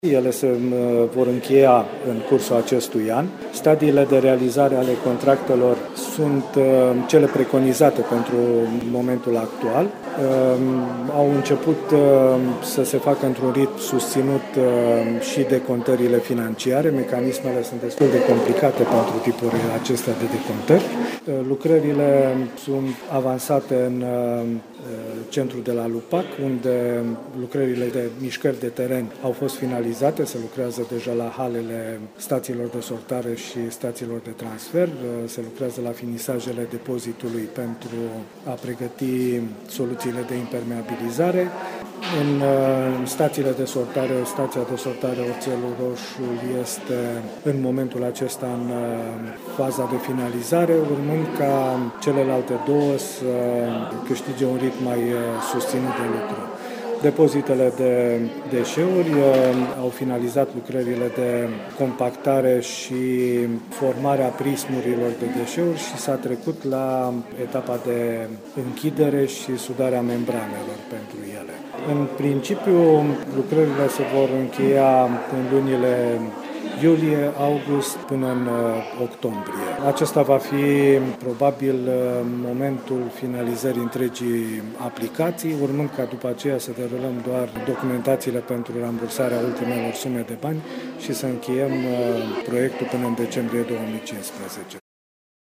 Ascultaţi declaraţia arhitectului şef al judeţului Caraş-Severin, Victor Naidan, manager de proiect: